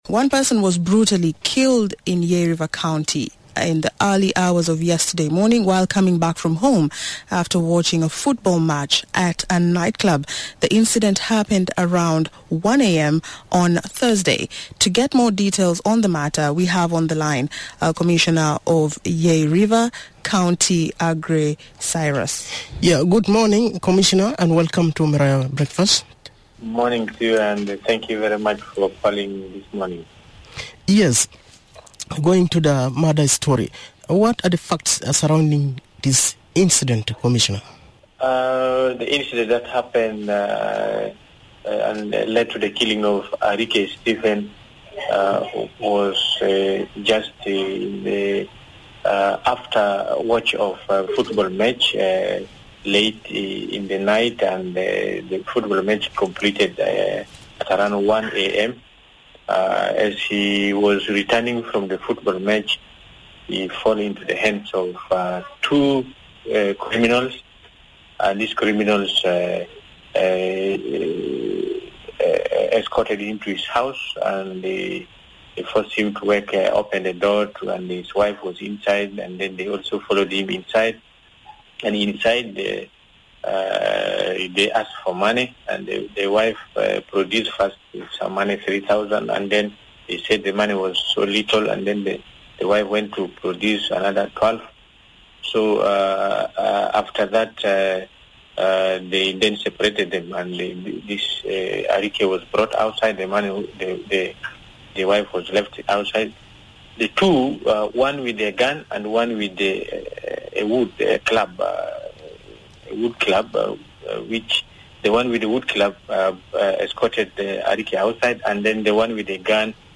He explains the details leading to the killing, in an interview.